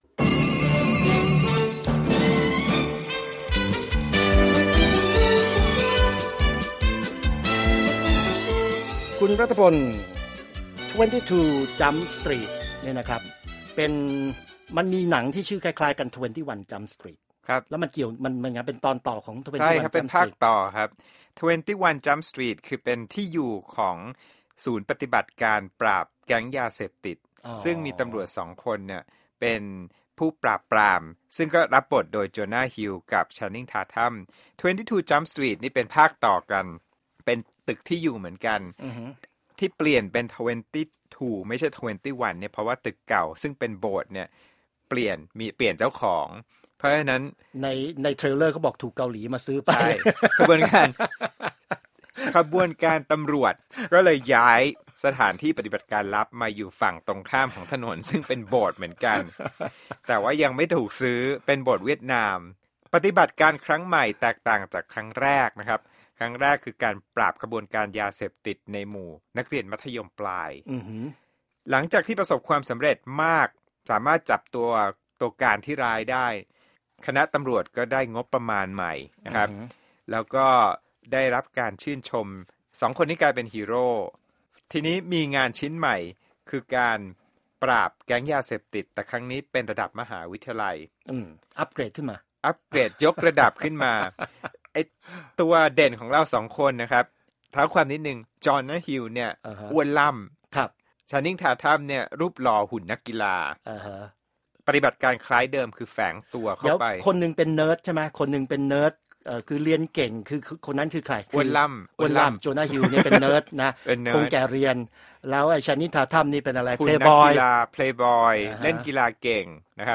movie-review